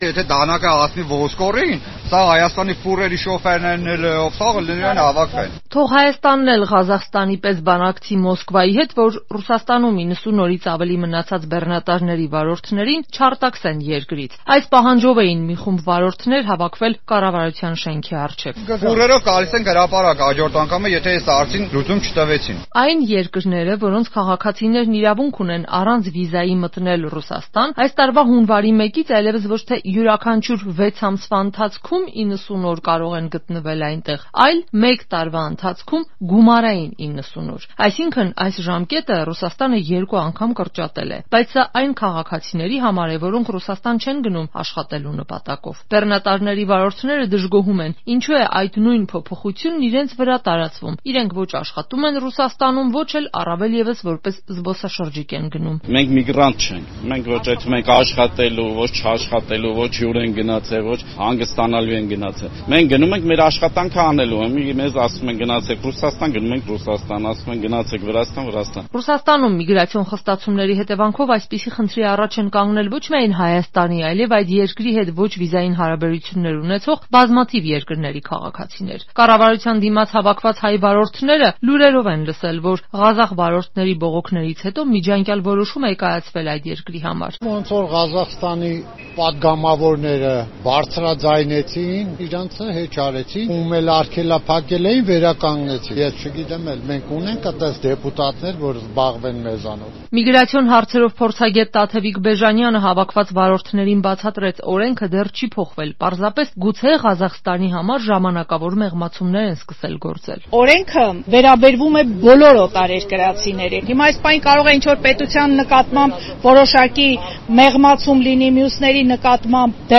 «Մենք միգրանտ չենք». ՌԴ բեռներ փոխադրող մի խումբ վարորդների բողոքի ակցիան՝ կառավարության շենքի առջև
Ռեպորտաժներ